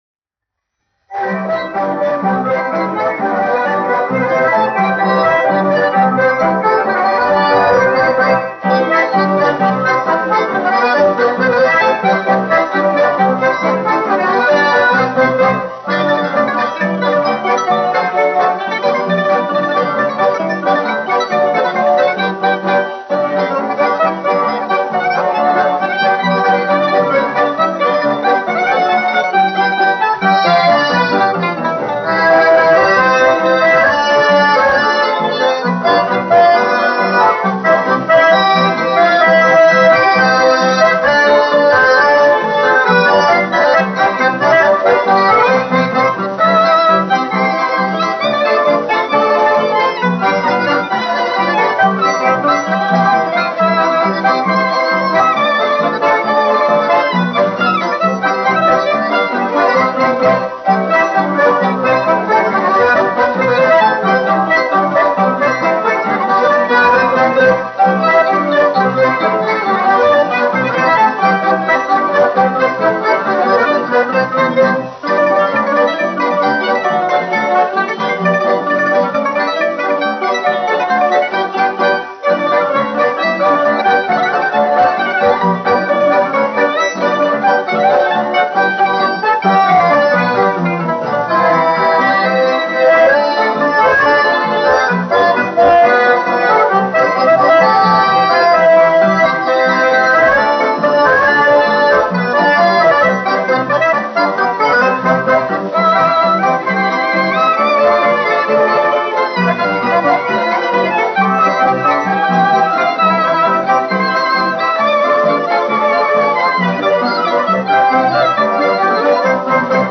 1 skpl. : analogs, 78 apgr/min, mono ; 25 cm
Polkas